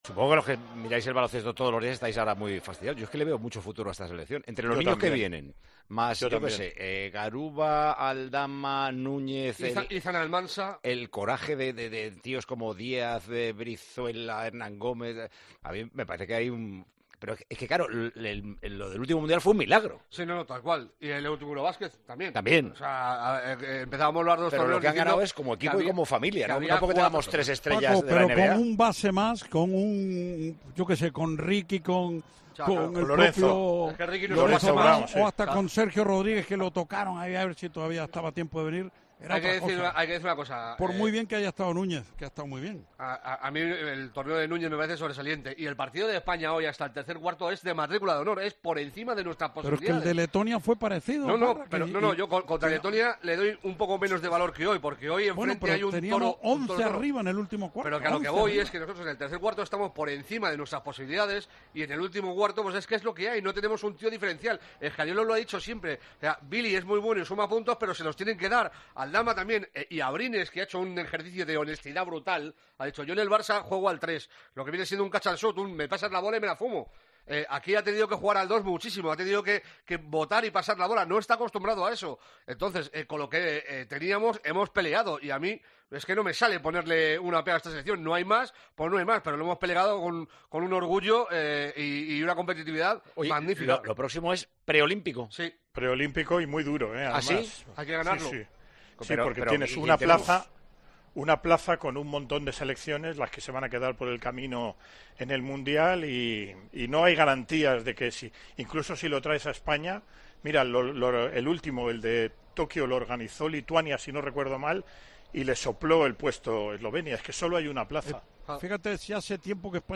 Los comentaristas de Tiempo de Juego analizan el futuro de la selección española de baloncesto